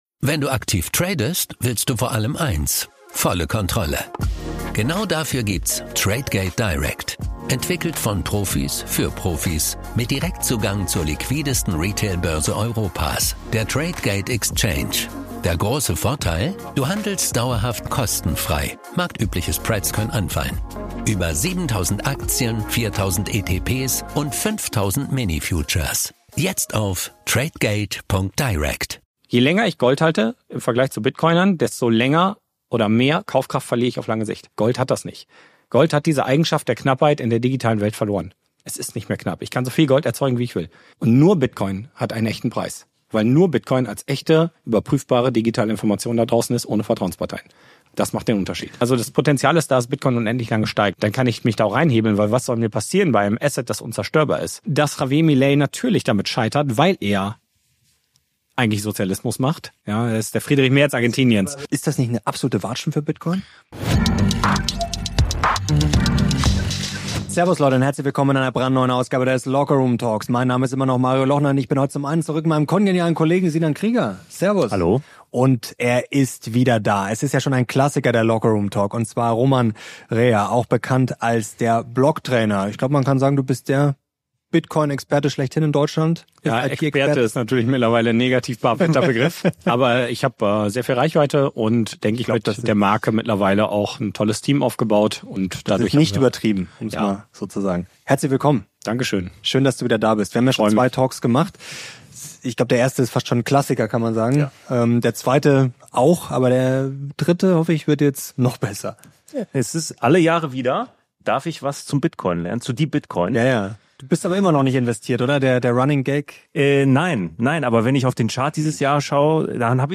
In diesem Interview erklärt Deutschlands bekanntester Bitcoin-Experte, warum Bitcoin aus seiner Sicht das härteste Geld der Welt ist – und warum der Preis langfristig sogar „ewig“ steigen könnte.